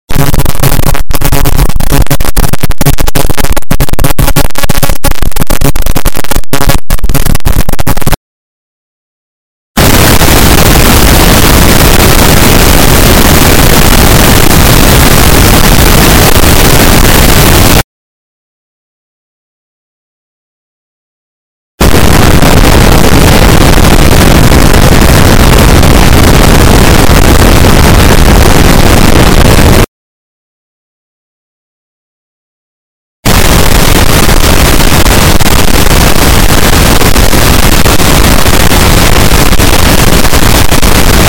Ultrasuoni spacca timpani - effetti